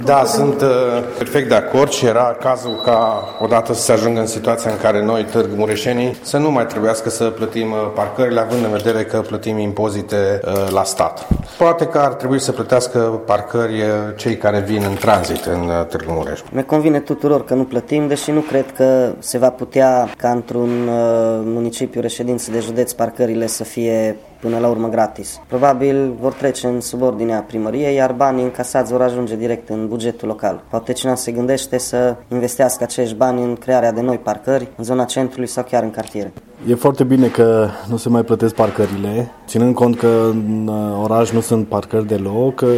Cei mai fericiți sunt tîrgumureșenii care au primit astfel un cadou la care nu se așteptau: